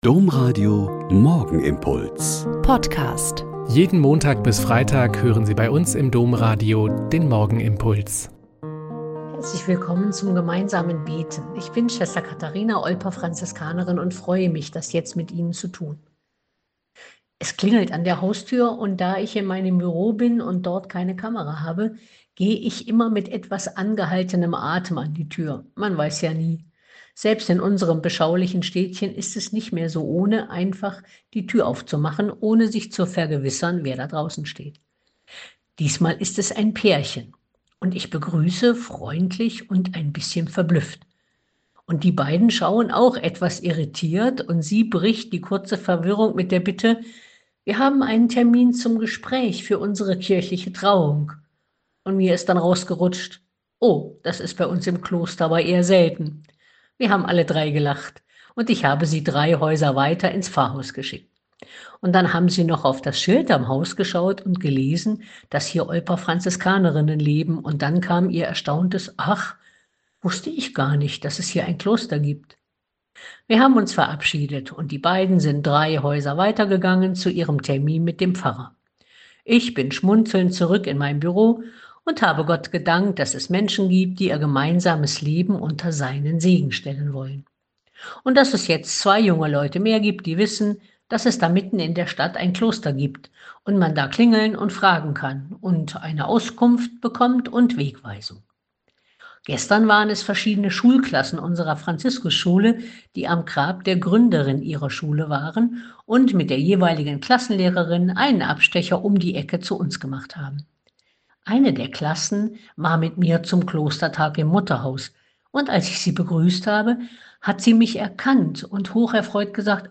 Mt 9,9-13 - Gespräch